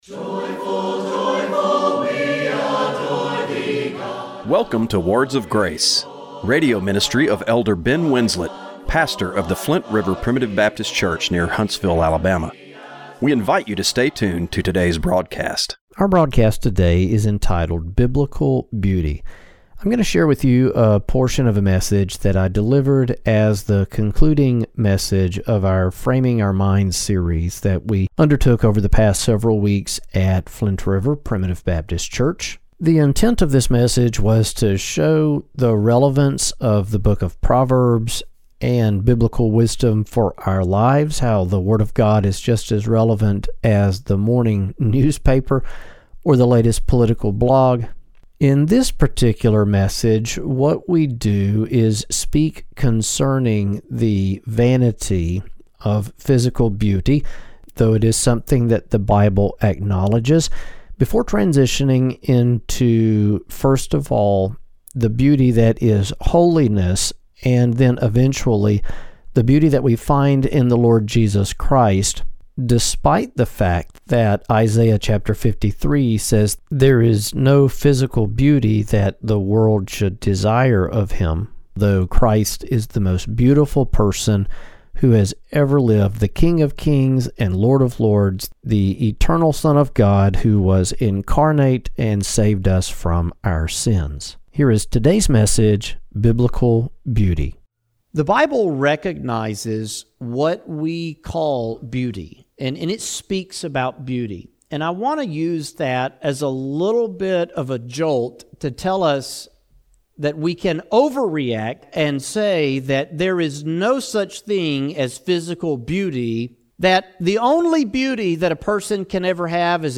Radio broadcast for April 27, 2025.